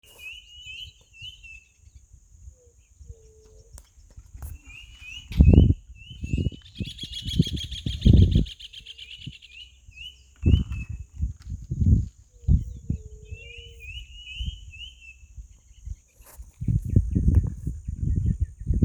Striped Cuckoo (Tapera naevia)
Condition: Wild
Certainty: Observed, Recorded vocal